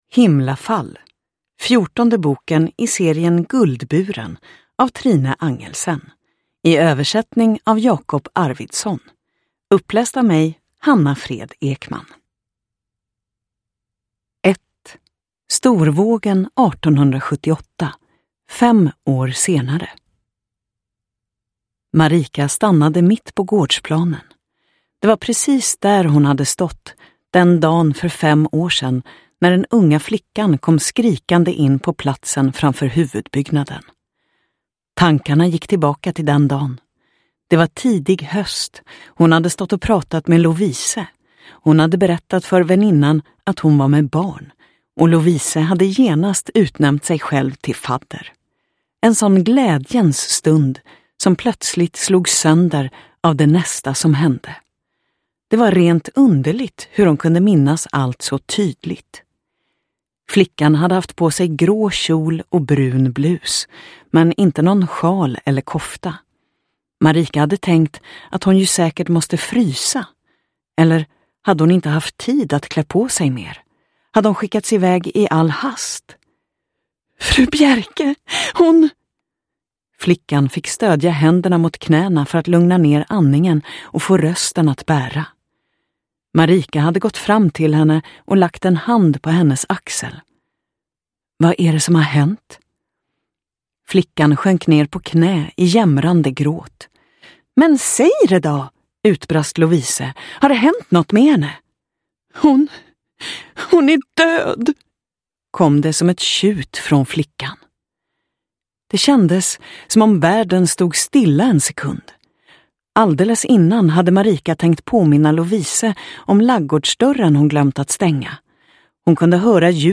Himlafall – Ljudbok